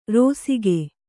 ♪ rōsige